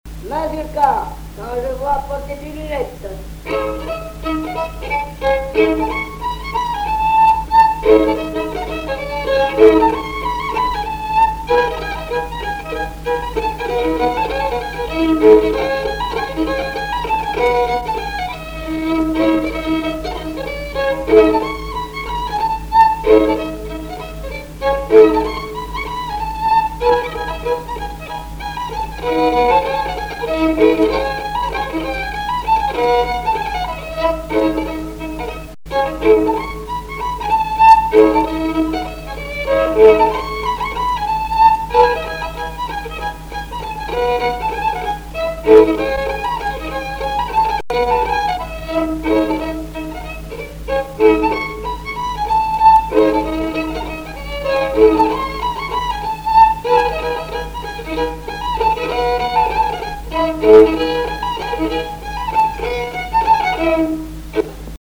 Chants brefs - A danser
danse : mazurka
Répertoire du violoneux
Pièce musicale inédite